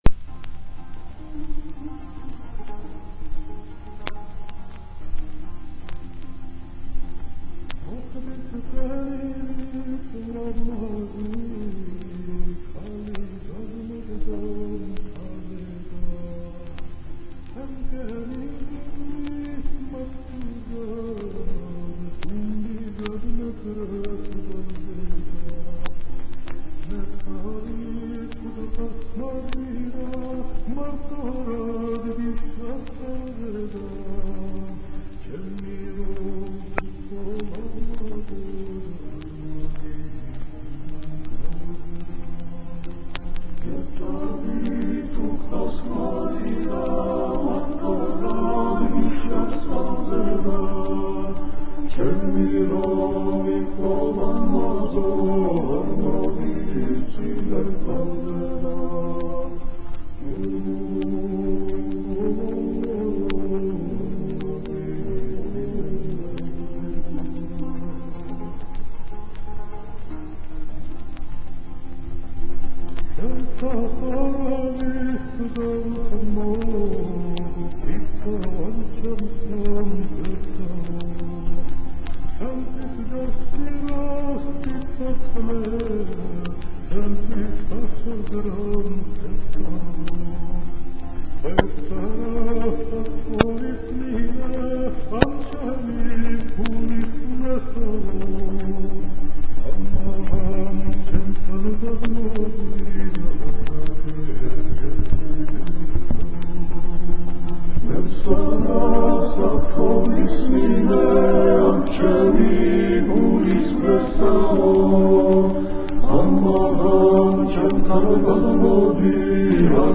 Hamlet Gonashvili sings Georgian Folk Songs